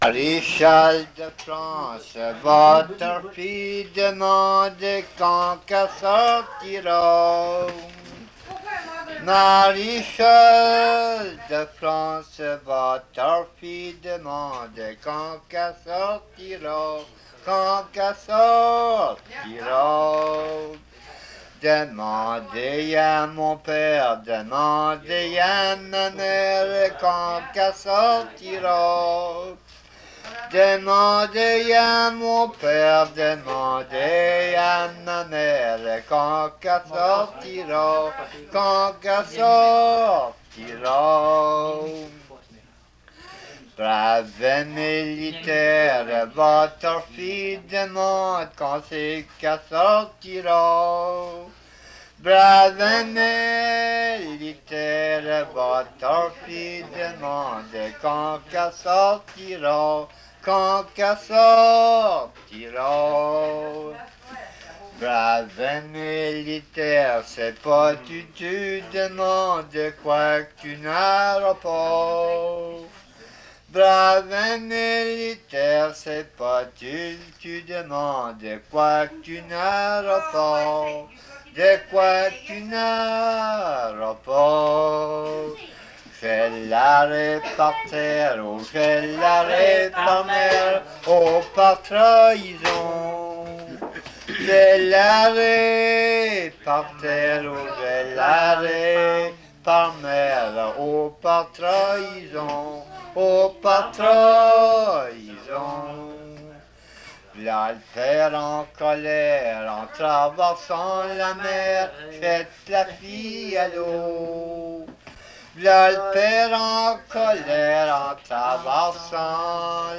Chanson Item Type Metadata
Emplacement L'Anse-aux-Canards